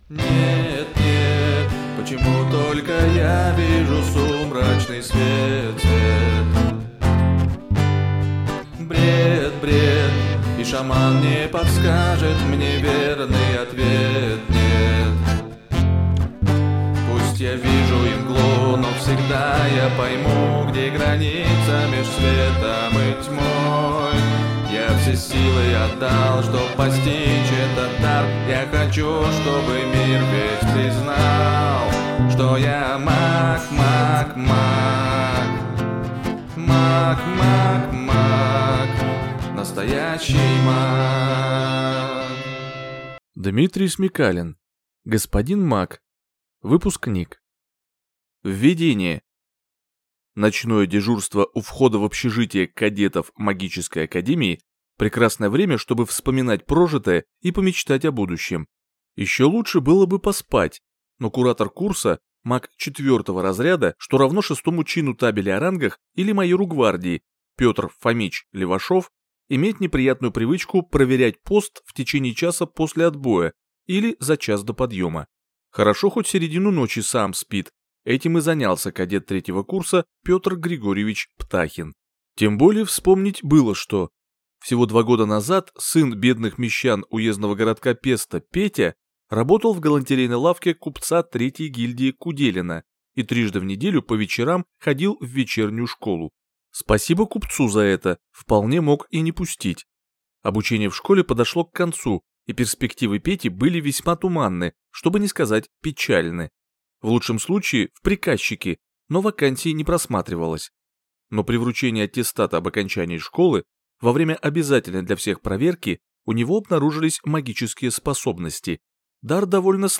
Аудиокнига Господин маг. Выпускник | Библиотека аудиокниг